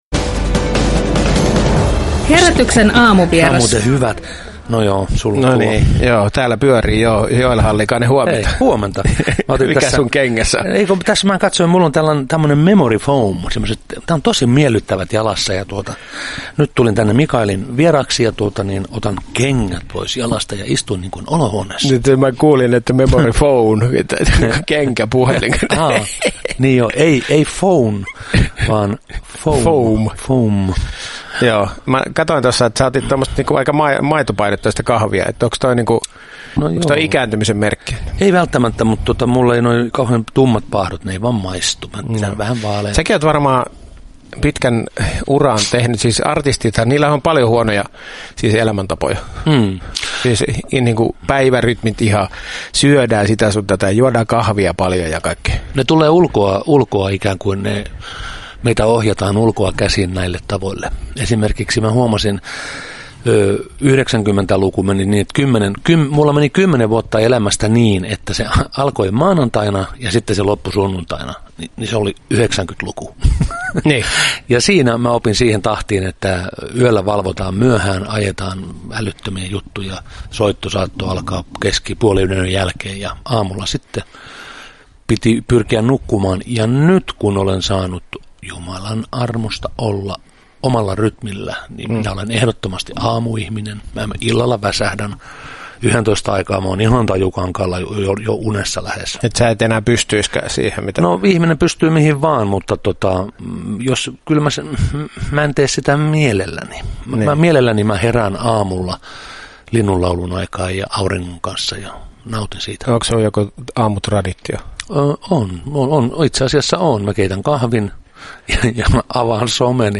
– Jeesusta en vaihda, sellaista syytä ei löydy, sanoo 60-vuotisjuhliaan viettävä kansantaitelija. Jokke käväisi Radio Dein Herätys! -aamulähetyksessä kertomassa tuntojaan, mitä on tullut tehtyä, mitä on meneillään ja mikä on viesti nykyihmiselle?